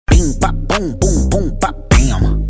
Heavy Rhythm, Unique Personality
Ringtone